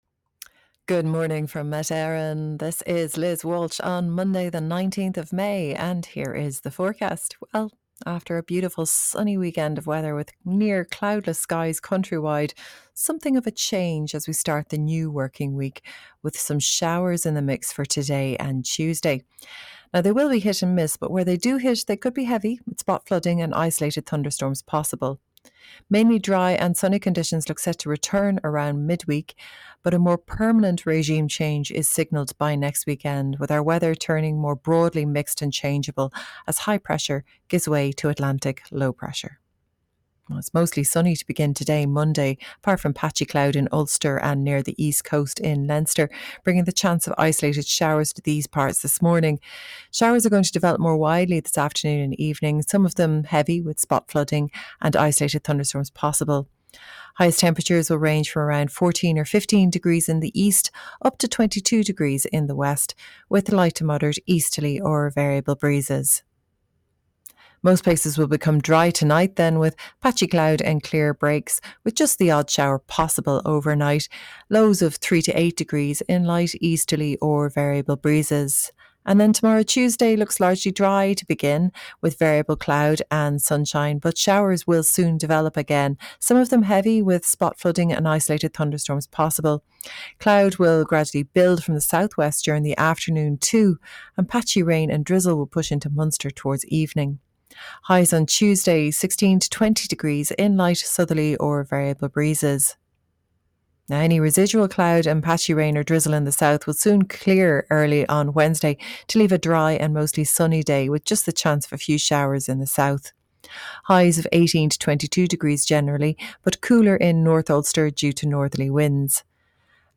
Weather Forecast from Met Éireann / Ireland's Weather 7am Monday 19 May 2025